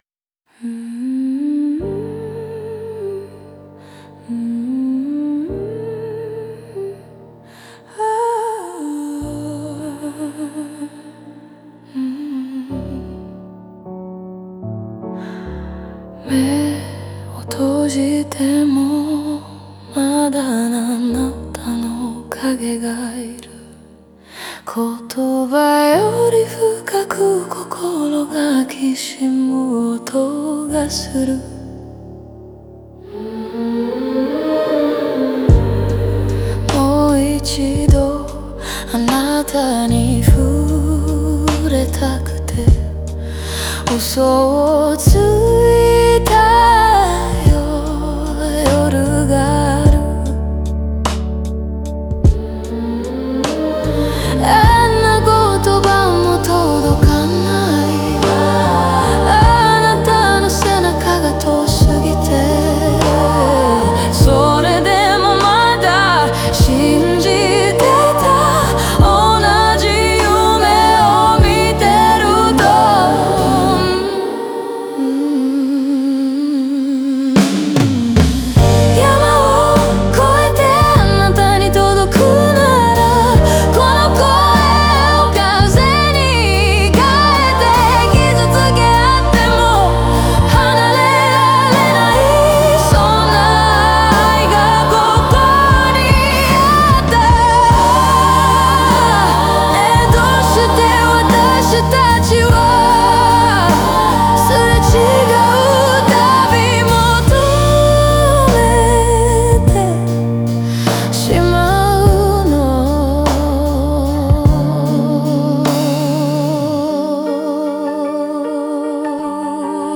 言葉にできない想いがハミングとして零れ、沈黙の中に愛の余韻が漂う。